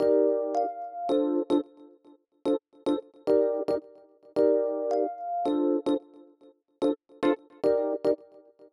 30 ElPiano PT2.wav